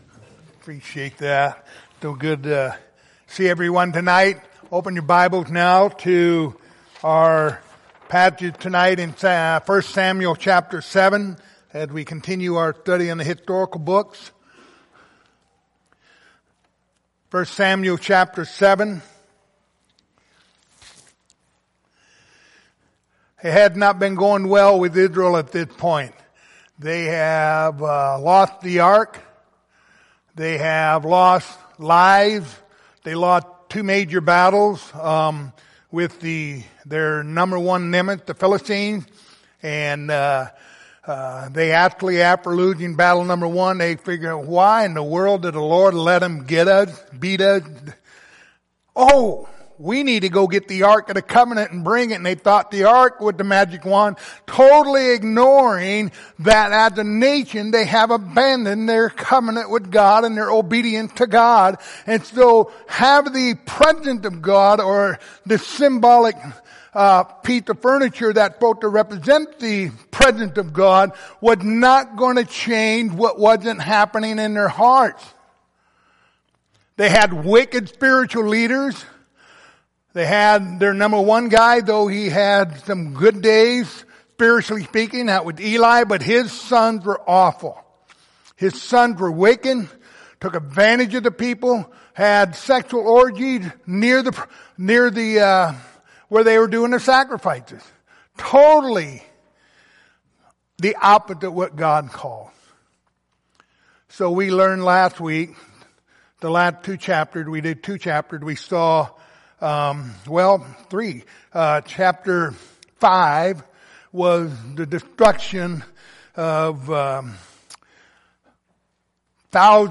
Passage: 1 Samuel 7:1-14 Service Type: Wednesday Evening